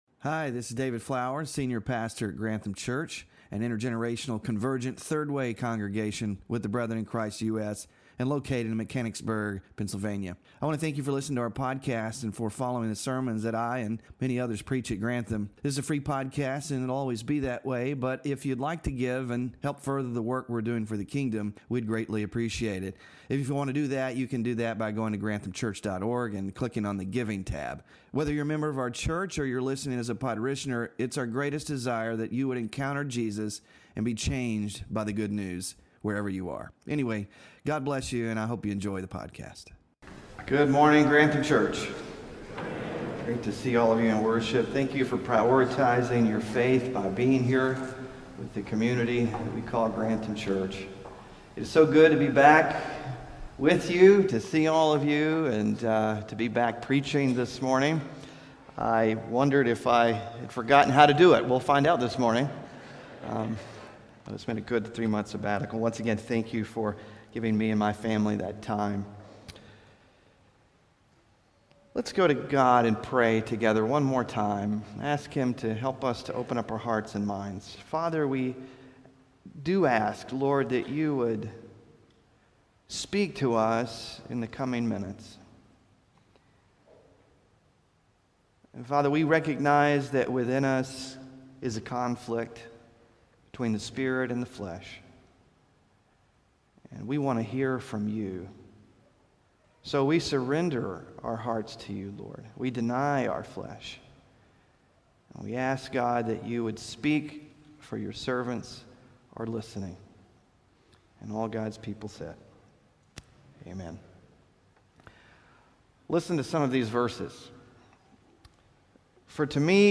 WORSHIP RESOURCES PHILIPPIANS SERMON SLIDES (1ST of 8 IN SERIES) SMALL GROUP DISCUSSION QUESTIONS (8-11-24) BULLETIN (8-11-24)